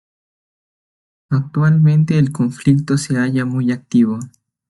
ac‧ti‧vo
/aɡˈtibo/